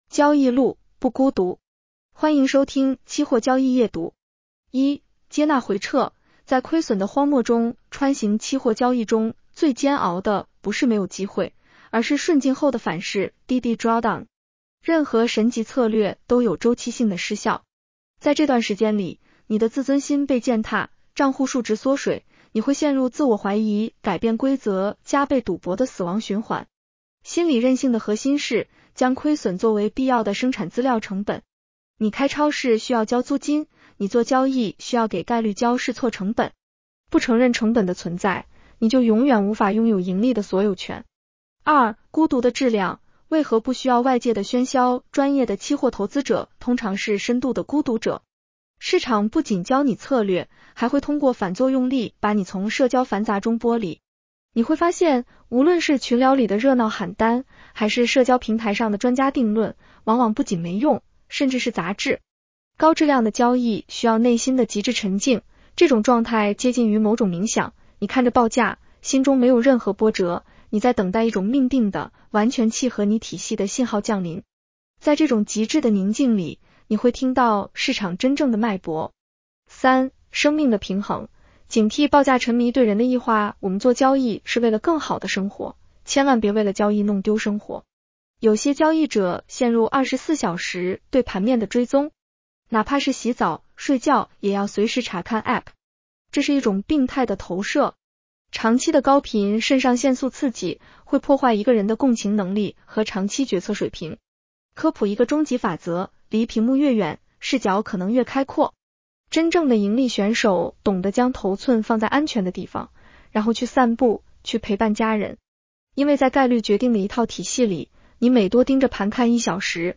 女声普通话版 下载mp3 交易路，不孤独。
（AI生成） 风险提示及免责条款：市场有风险，投资需谨慎。